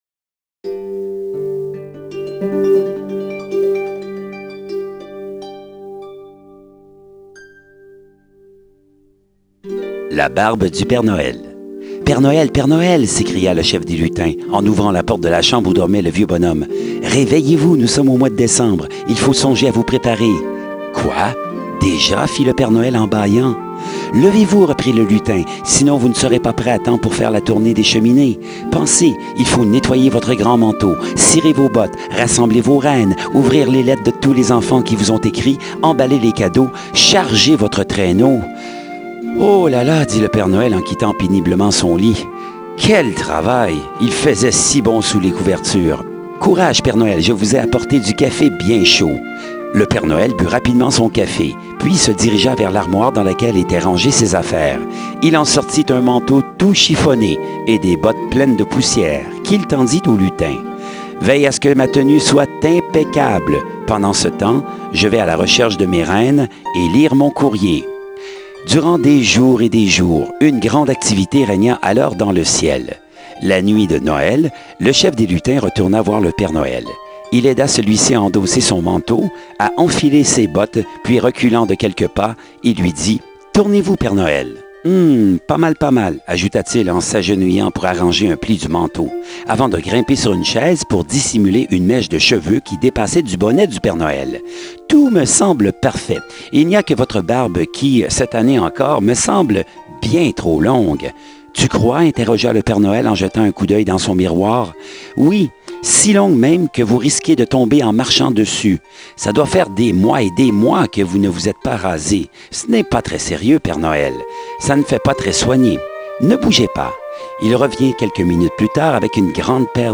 Musique de fond; Le canon de Pachelbel ( violons en 432 htz )
(NB: Qualité sonore moyenne )